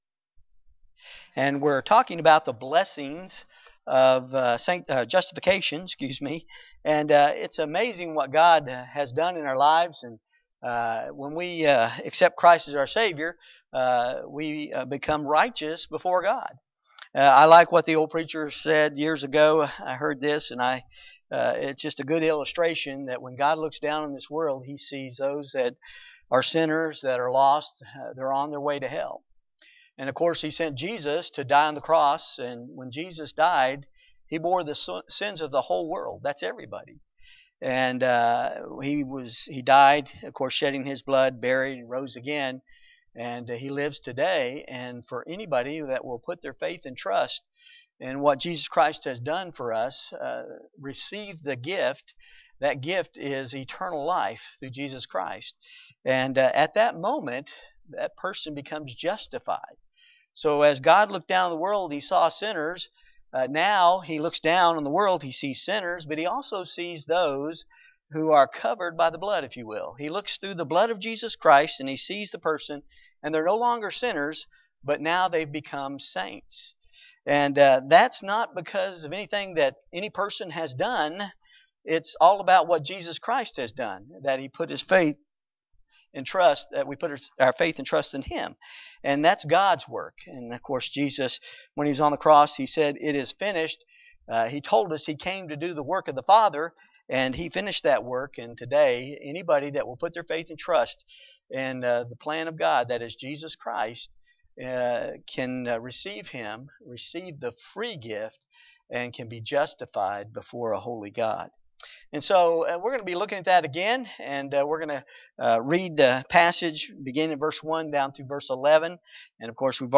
Passage: Romans 5:1-11 Service Type: Wednesday Bible Study
Topics: Preaching